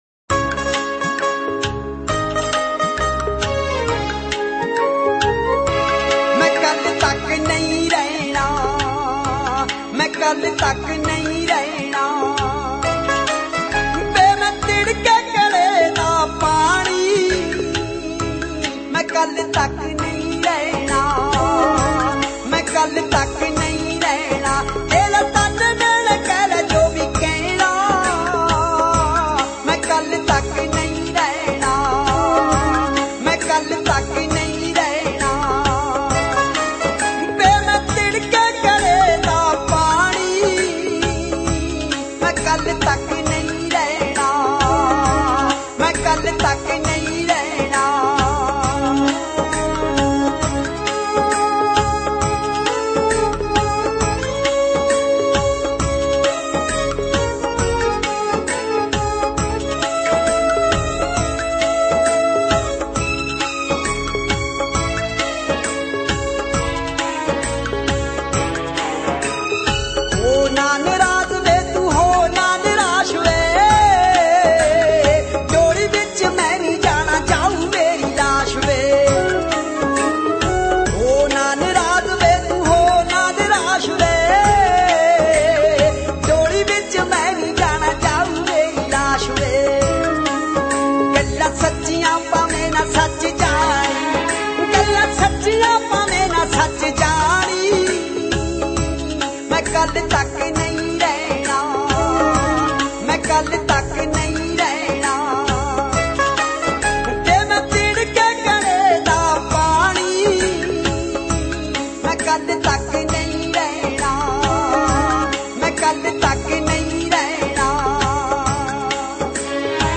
Haryanvi Ragani